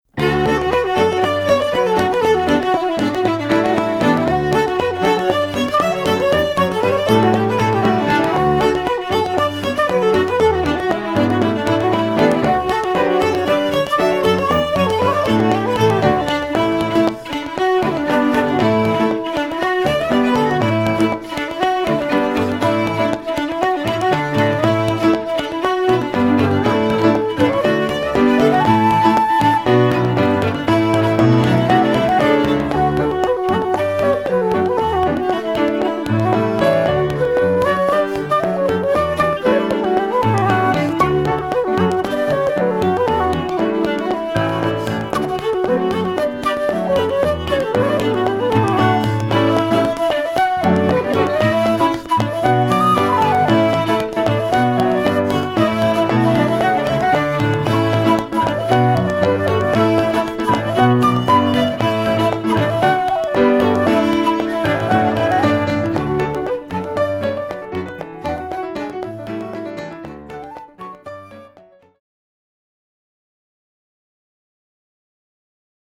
piano, concertina, banjo, foot percussion, backing vocals
flute, Scottish border pipes, whistle, guitar, lead vocals
fiddle, low whistle, backing vocals
fiddle, bodhran, djembe